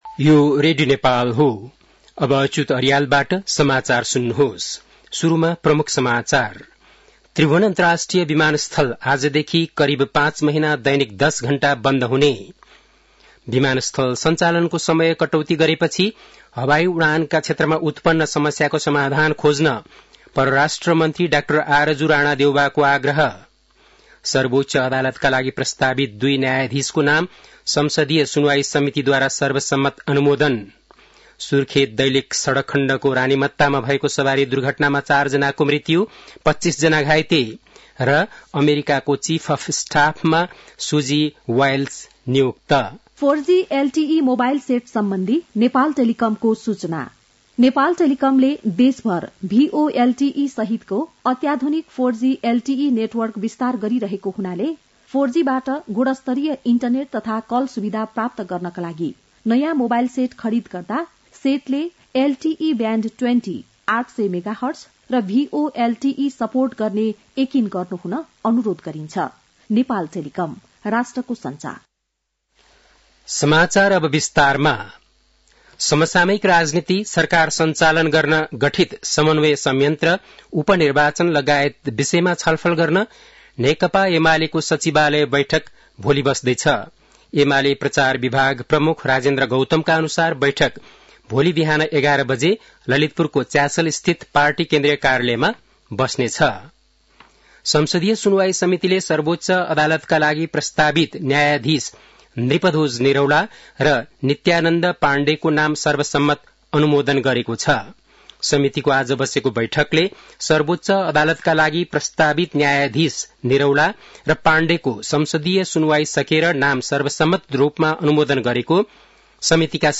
An online outlet of Nepal's national radio broadcaster
बेलुकी ७ बजेको नेपाली समाचार : २४ कार्तिक , २०८१